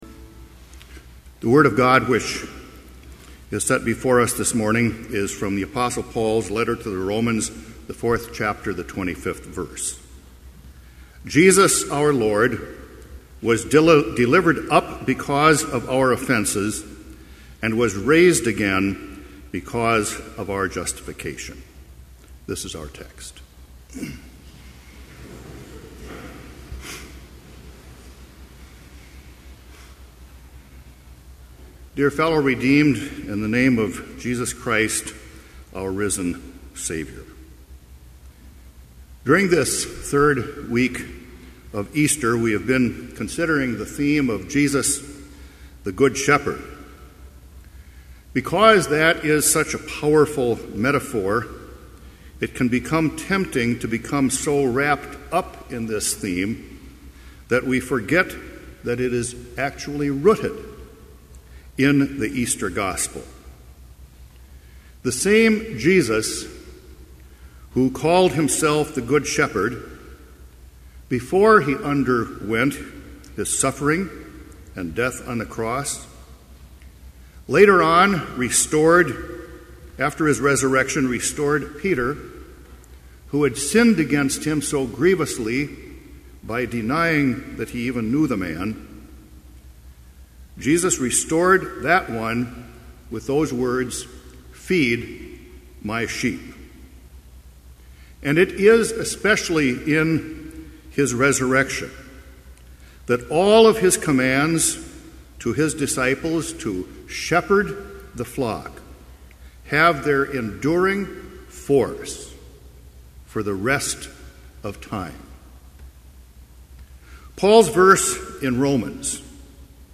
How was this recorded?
Chapel service on May 12, 2011, at Bethany Chapel in Mankato, MN, (audio available)